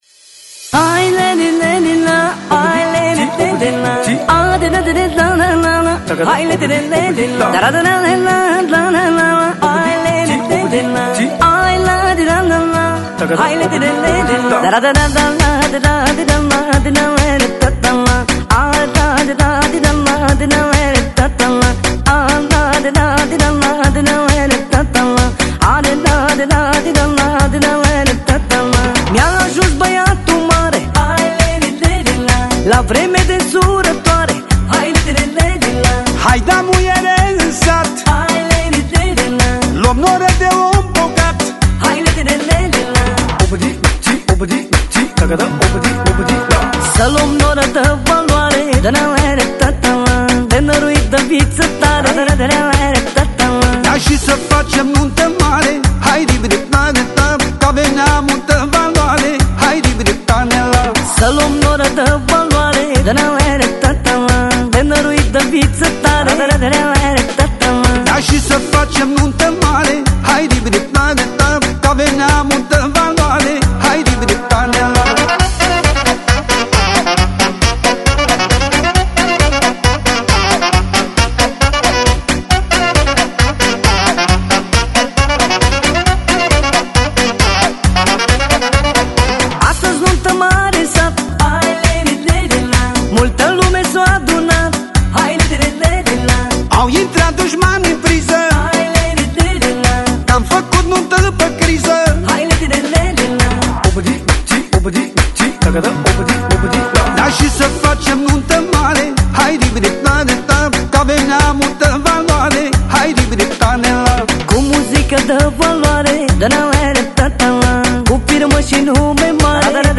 Один из самых известных исполнителей в стиле манеле.